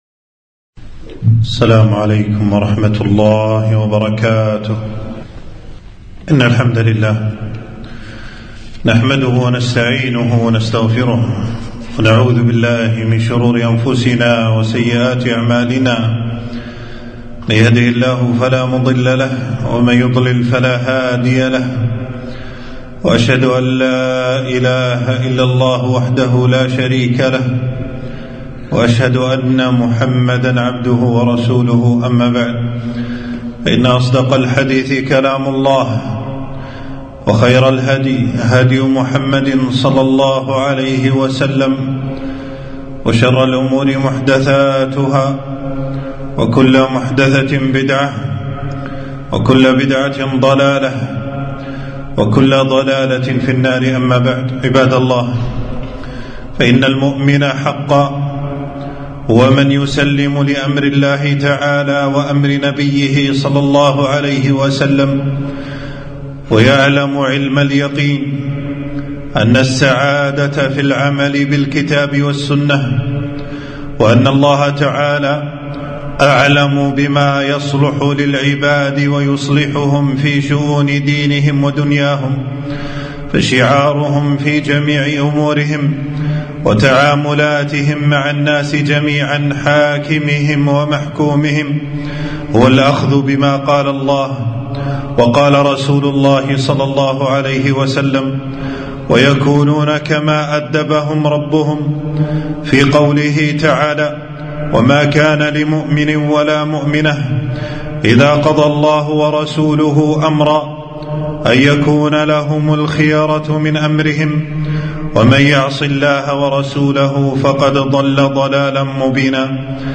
خطبة - احذروا دعاة المظاهرات والفتن